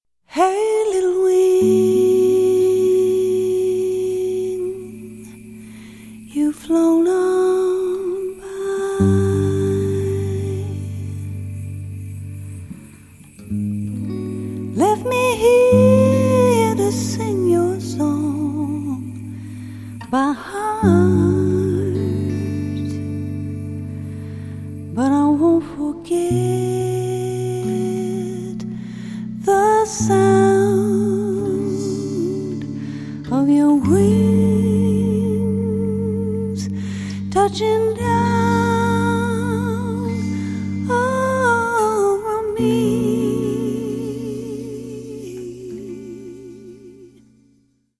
Drums
Vocals, Guitar